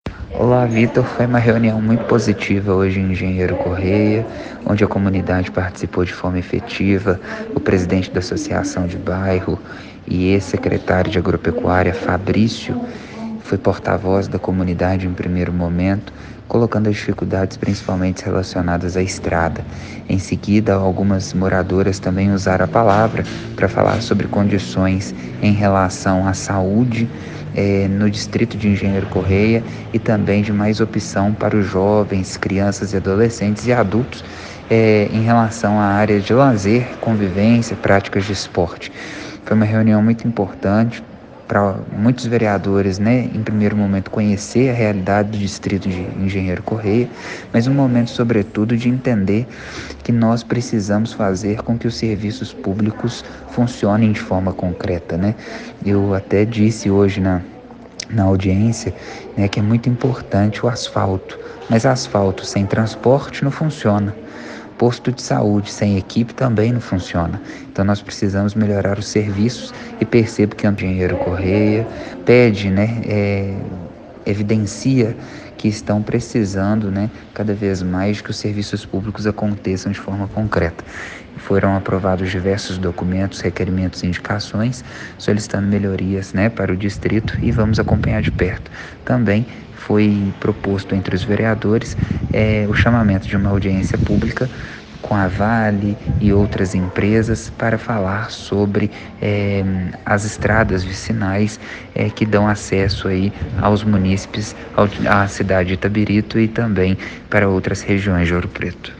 Ouça a entrevista completa do vereador Matheus Pacheco ao Diário de Ouro Preto